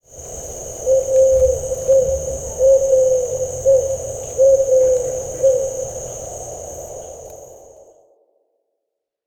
Ringetone Uhu
Kategori Dyr